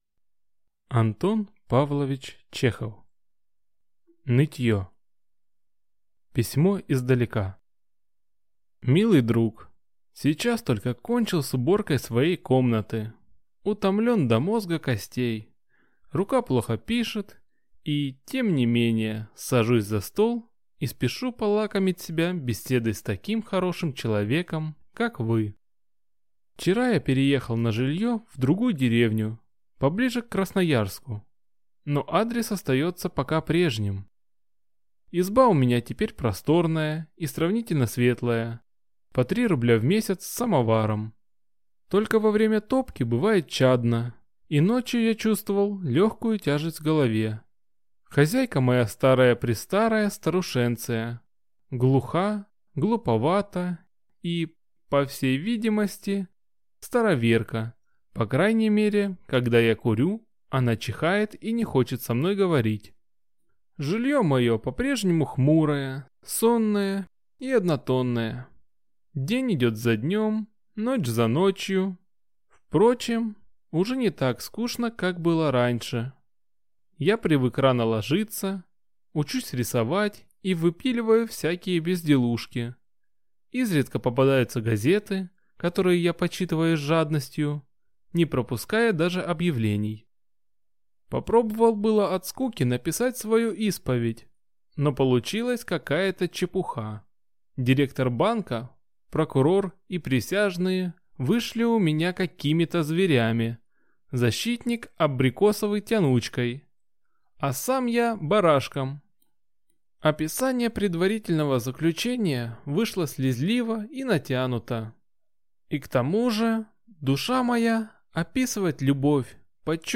Аудиокнига Нытье | Библиотека аудиокниг